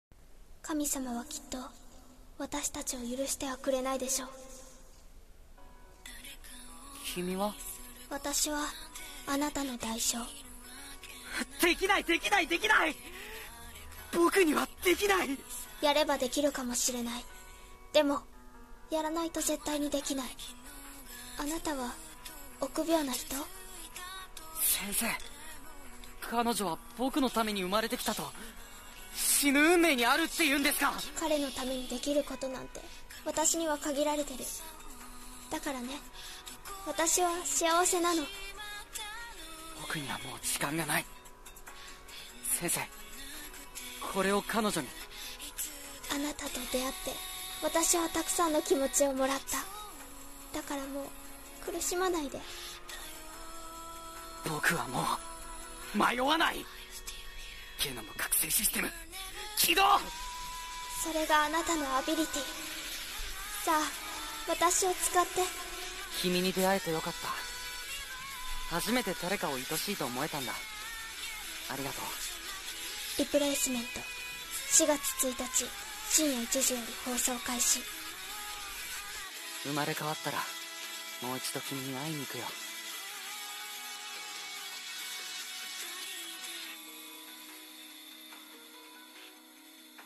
【声劇】アニメ告知風CM『リプレイスメント』